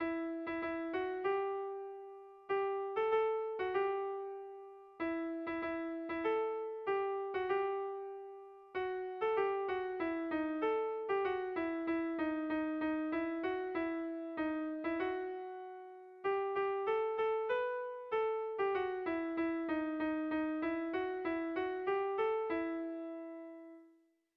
Erlijiozkoa
Seiko handia (hg) / Hiru puntuko handia (ip)
ABD